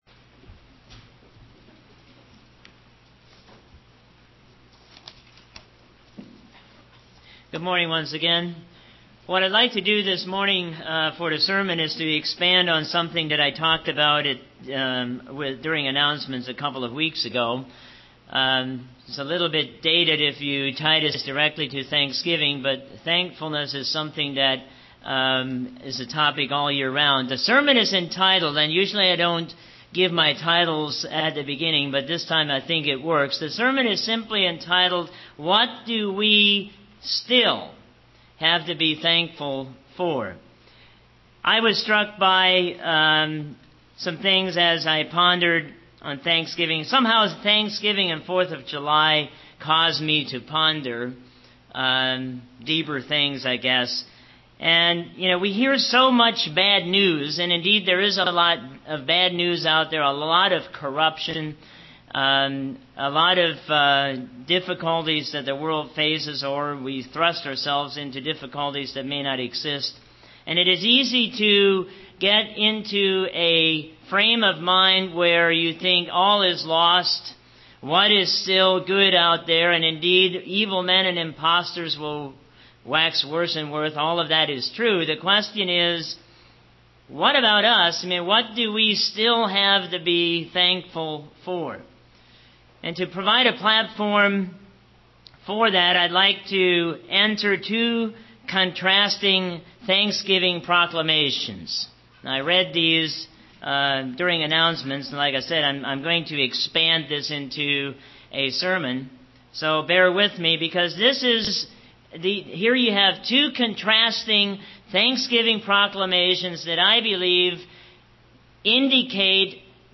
A four point sermon explaining major blessings from God.